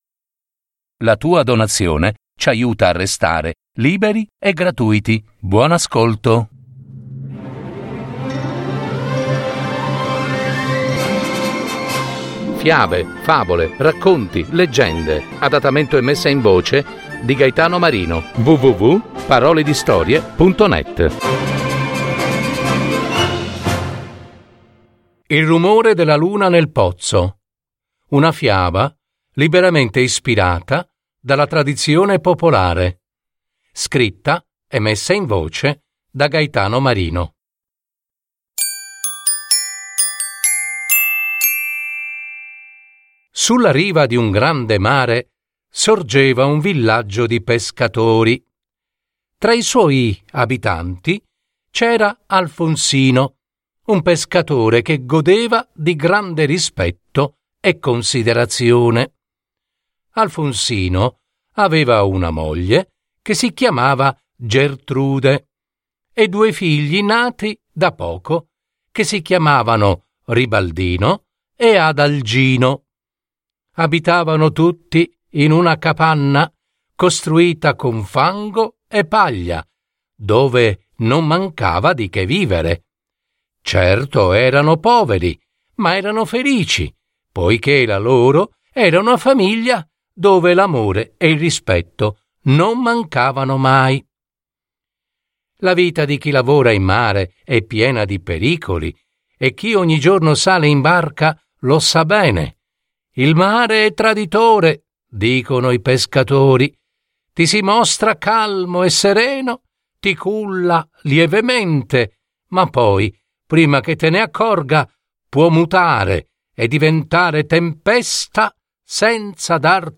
Il rumore della Luna nel pozzo. Fiaba